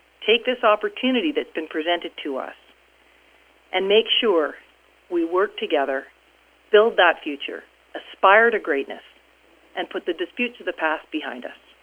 Premier Christy Clark weighed in on the court ruling…